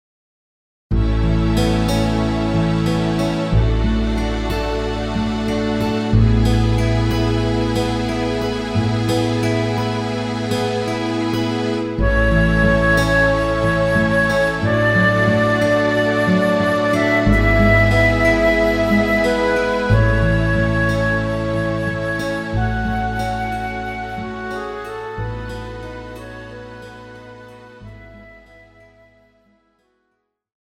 KARAOKE/FORMÁT:
Žánr: Vánoční
BPM: 91
Key: A